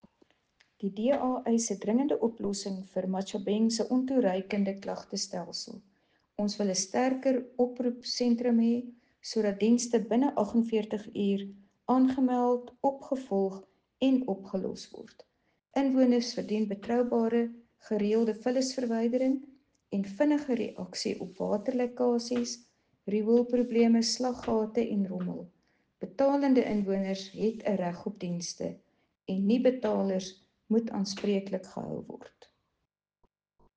Afrikaans soundbites by Cllr Marie van Rooyen and